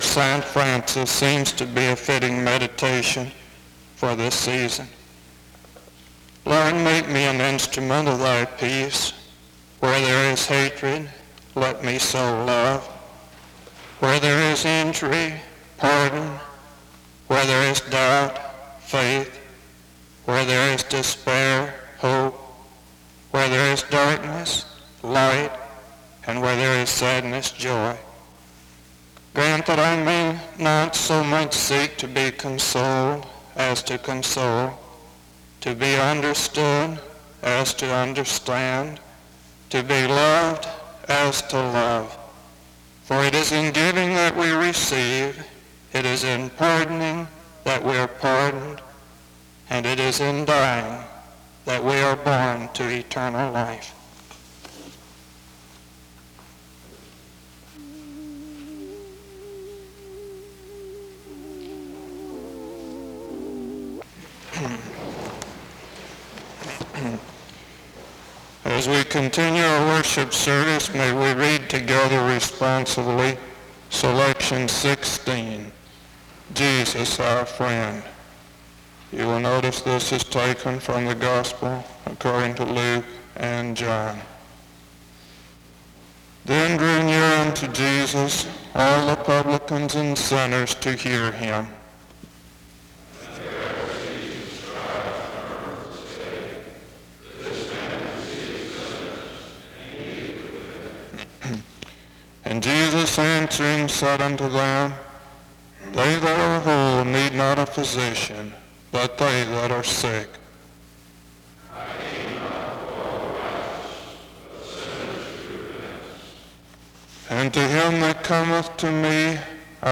The service begins with a word of prayer from 0:00-1:07. A responsive reading takes place from 1:10-2:47. Another prayer is offered from 2:48-6:46. Music plays from 6:58-11:14.